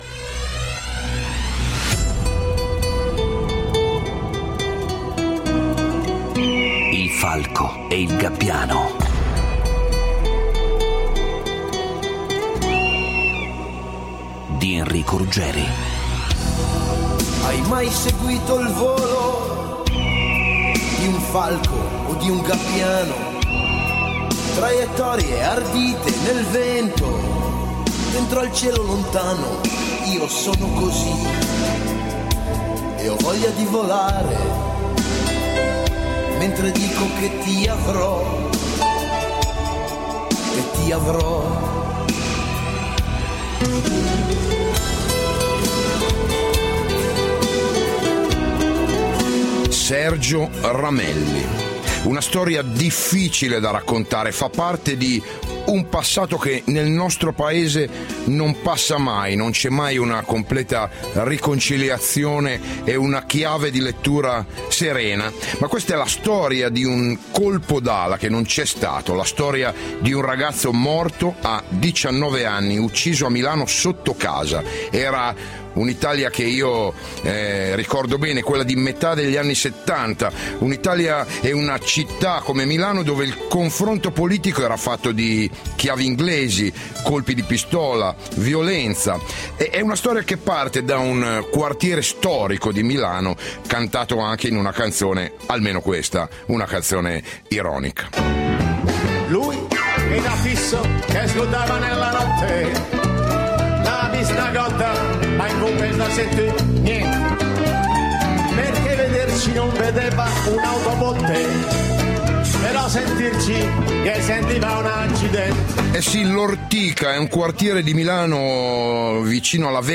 Il 12 giugno 2015 Enrico Ruggeri ha parlato di Sergio a “Il Falco e il Gabbiano”, rompendo un altro muro di silenzio.